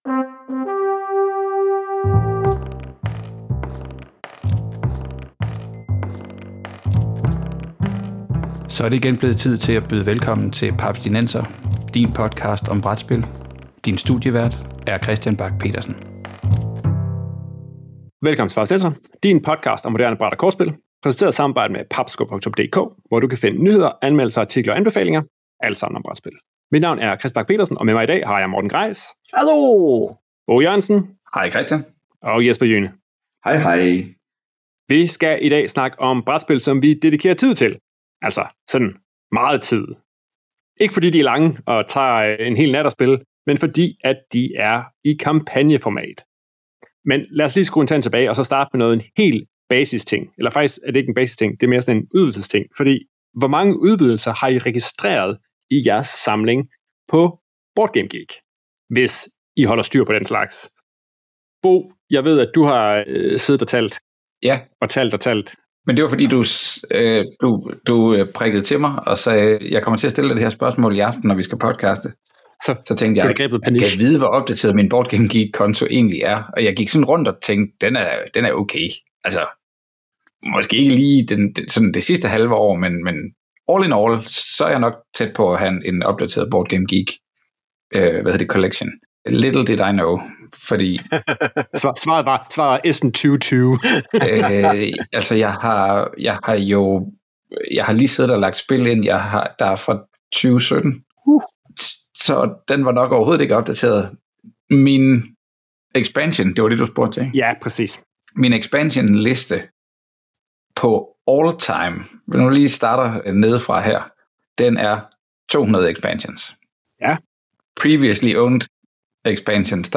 Derudover får vi også lige vendt, hvor mange brætspilsudvidelser der ligger hos de fire i studiet.